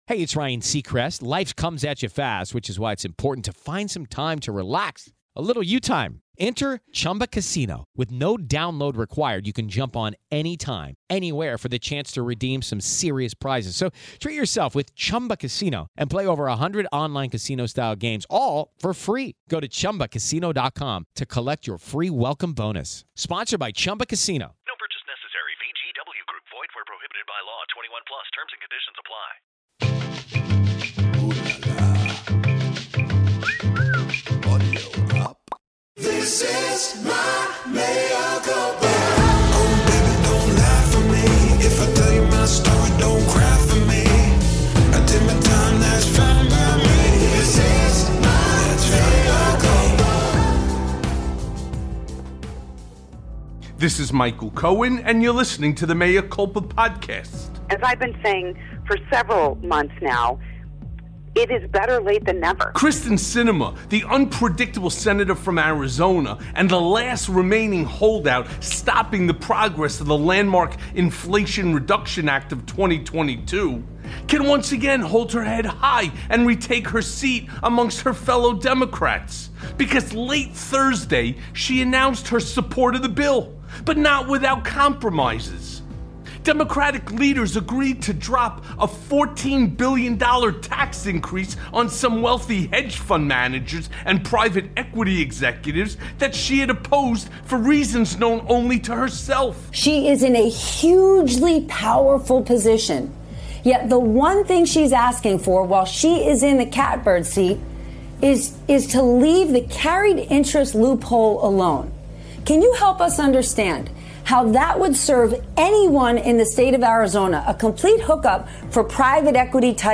Breaking!!! DOJ Will Indict Trump After Midterms + A Conversation with Glenn Kirschner
Mea Culpa welcomes back Glenn Kirschner, a former federal prosecutor with 30 years of trial experience.